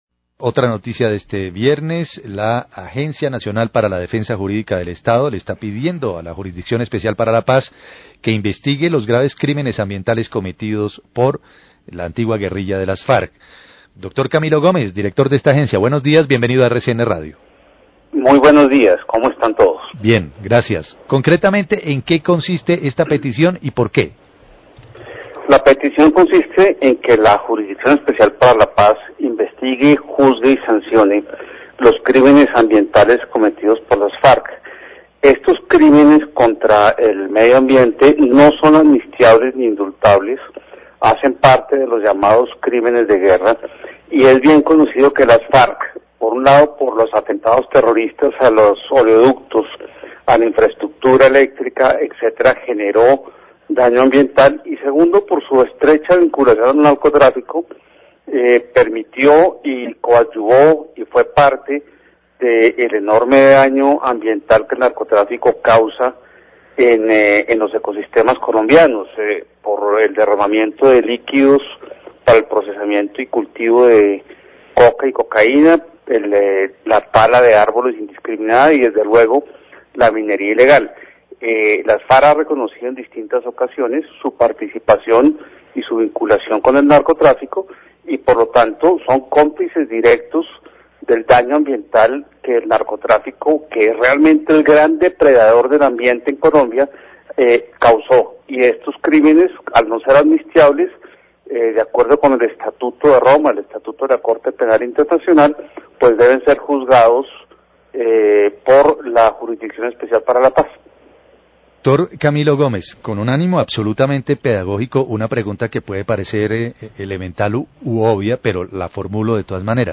Radio
El director de la Agencia Nacional para la Defensa Jurídica del Estado, Camilo Gómez, habla de la petición que presentó ante la JEP, para que se investigue a los integrantes de las antiguas Farc por los delitos ambientales que fueron cometidos durante el conflicto armado.